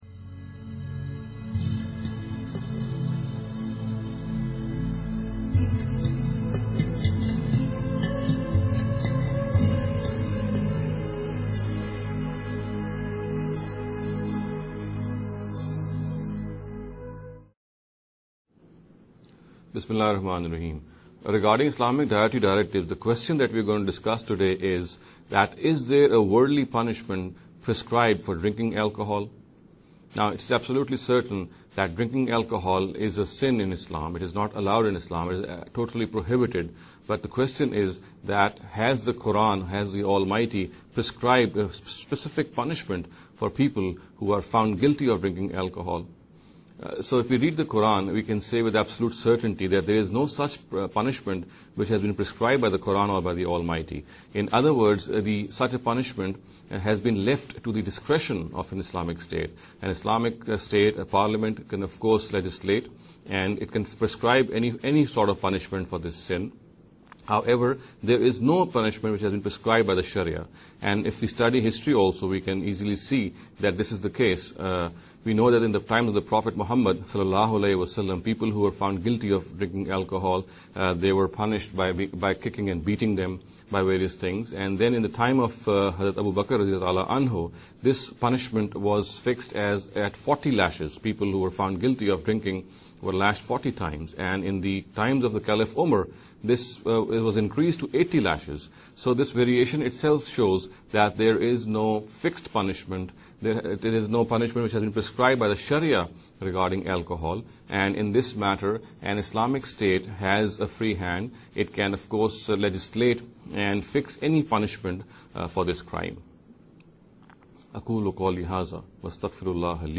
This lecture series will deal with some misconception regarding The Dietary Directives of Islam.